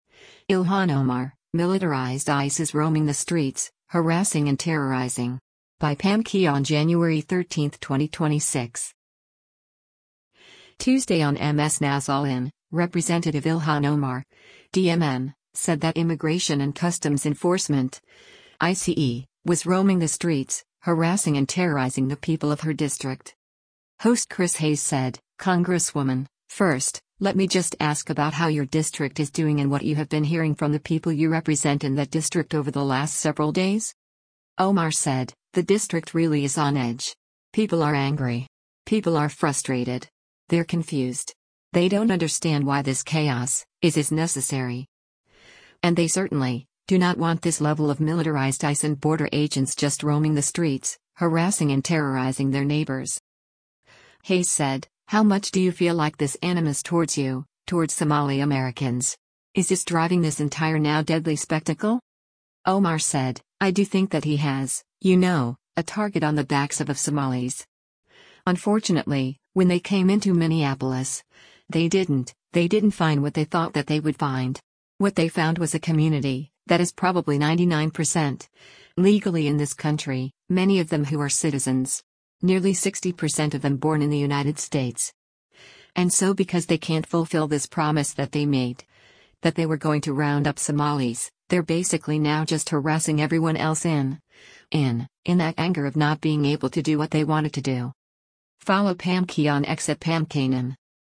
Tuesday on MS NOW’s “All In,” Rep. Ilhan Omar (D-MN) said that Immigration and Customs Enforcement (ICE) was “roaming the streets, harassing and terrorizing” the people of her district.